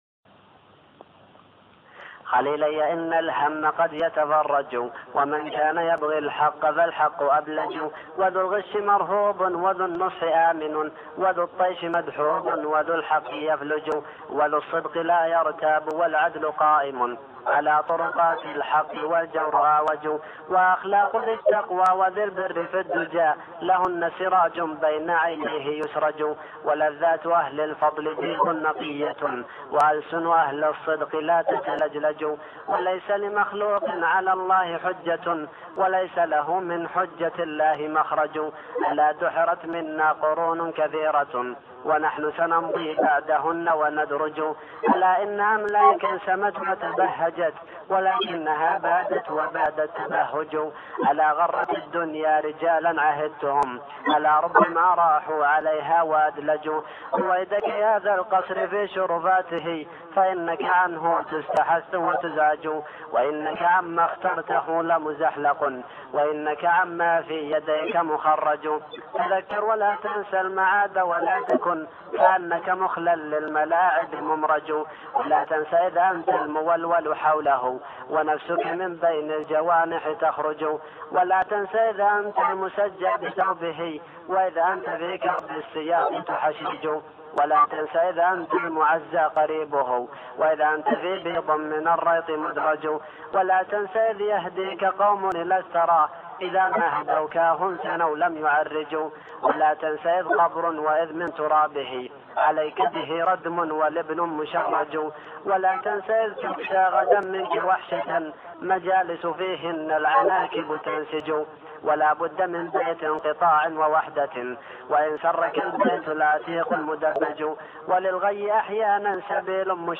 تلاوة